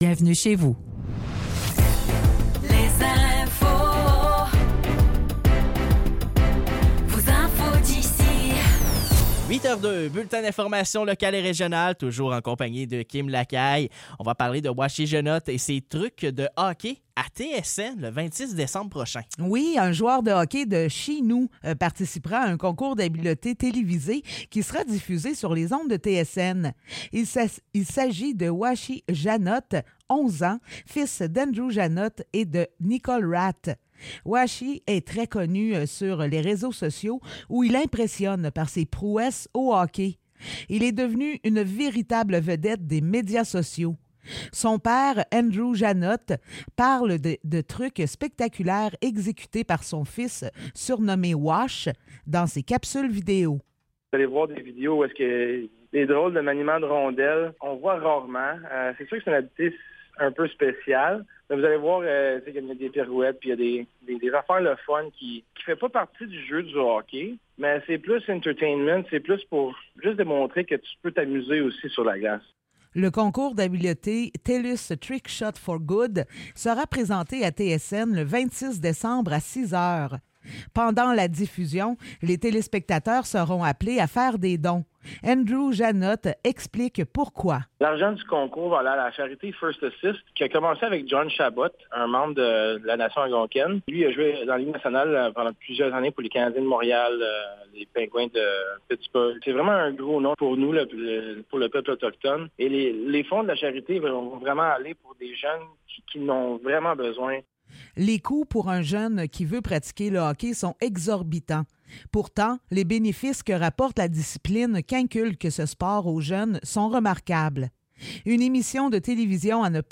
Nouvelles locales - 22 décembre 2023 - 8 h